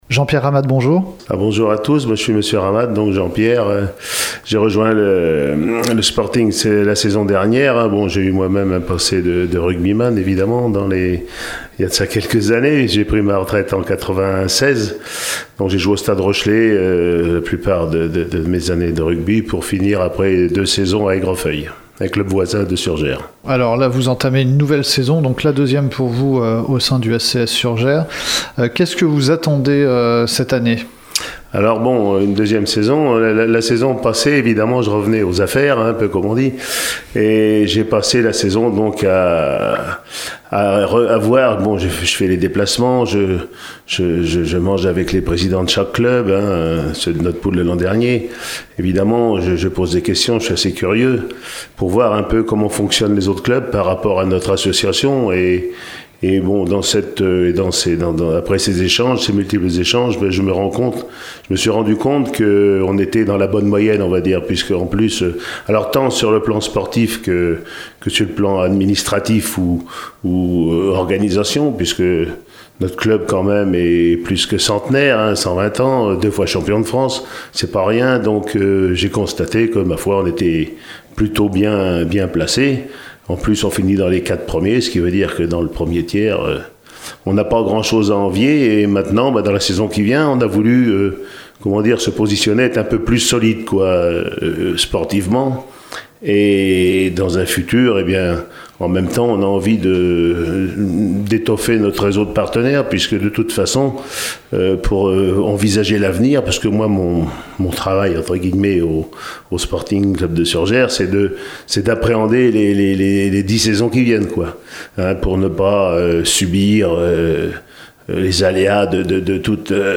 Rugby / Fédérale 2 : interview